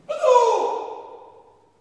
Categories: Funny Sounds
Karate Shout Sound
8d82b5_Karate_Shout_Sound_Effect-1.mp3